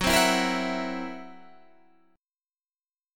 F#7b5 chord